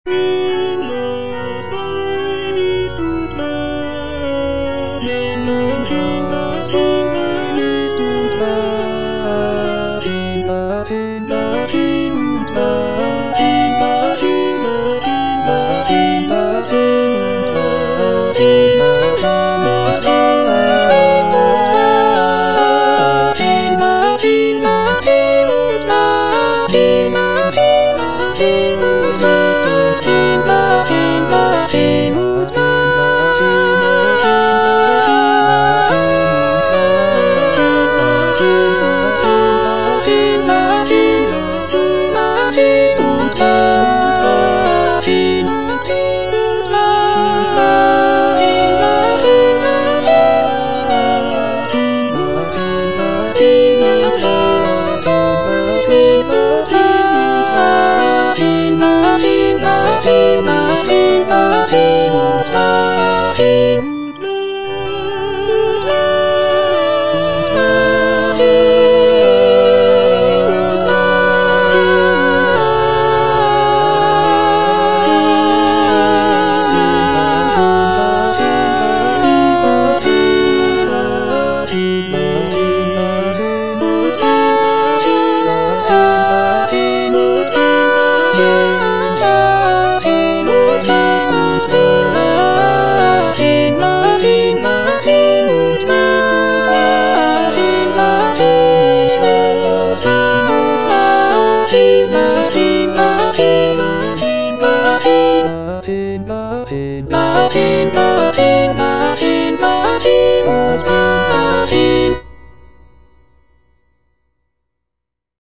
Cantata
Alto (Emphasised voice and other voices) Ads stop